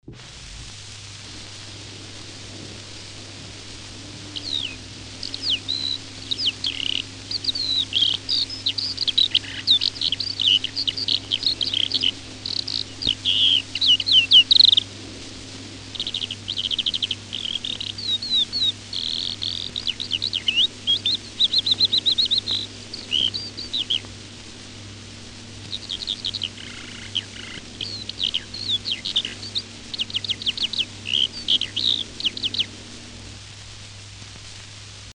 Die Feldlerche
1935 erschienen im Verlag von Hugo Bermühler in Berlin-Licherfelde insgesamt drei Schallplatten mit dem Titel „Gefiederte Meistersänger“, die die Singstimmen von Vögeln dokumentieren.